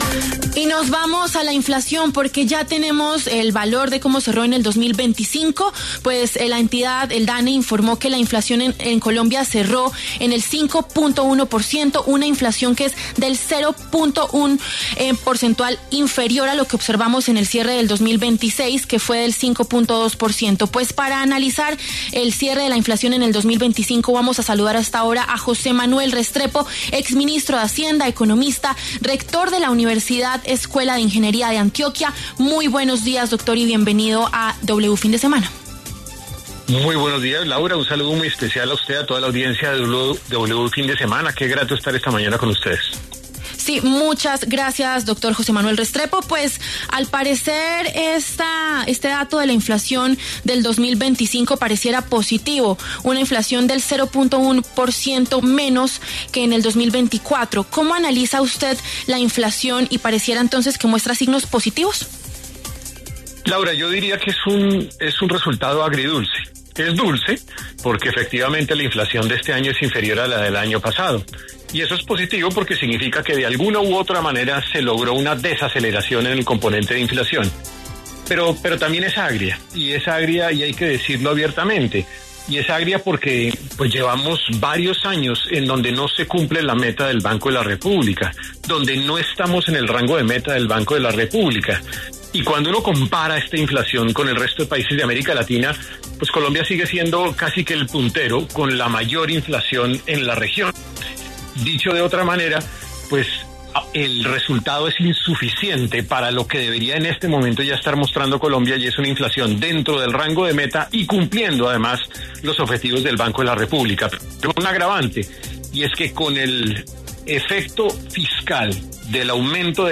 El economista y exministro José Manuel Restrepo explicó en W Fin de Semana las implicaciones del dato de inflación para el cierre del año 2025, el cual se ubicó en 5,10%.